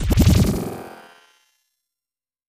Music Effect; Record Scratch, With Ascending Flutter Echoes.